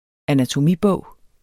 Udtale [ anatoˈmiˌbɔˀw ]